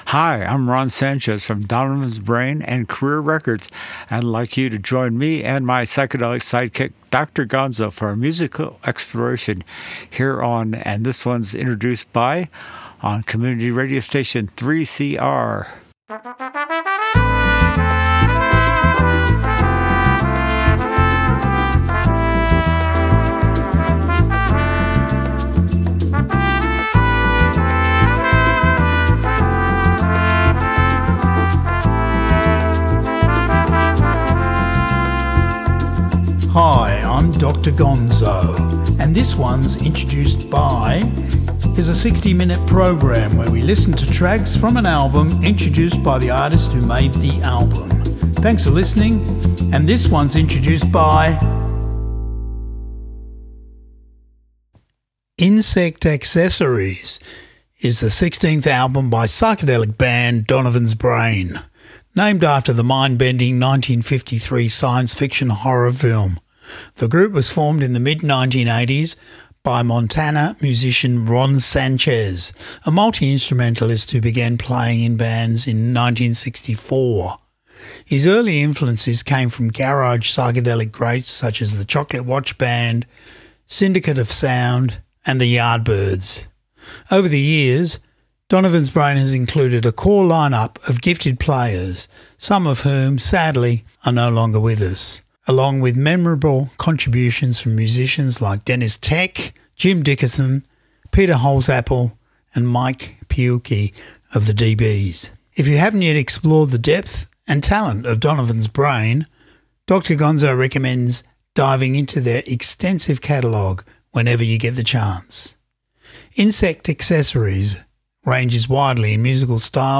3CR Community Radio